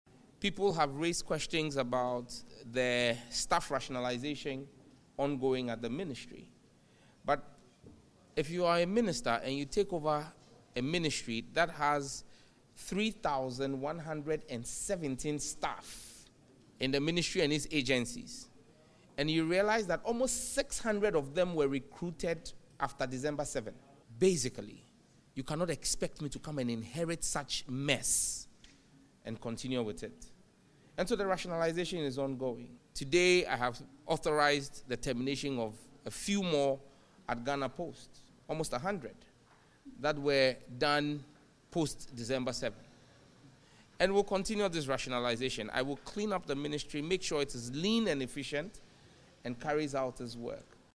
“Today, I have authorized the termination of a few more at Ghana Post—almost 100 that were recruited after the election,” he told journalists.